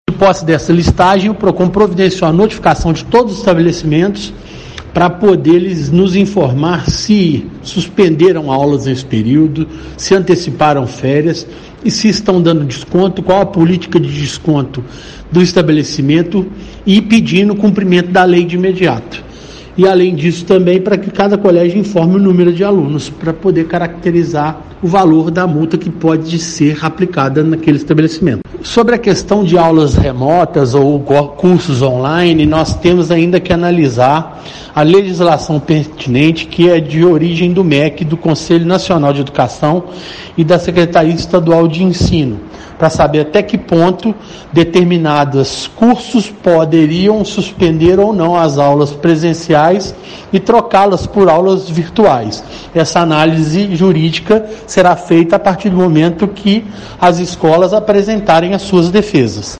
O superintendente do Procon/JF, Eduardo Schröder, explica como está sendo feita a comunicação com as escolas.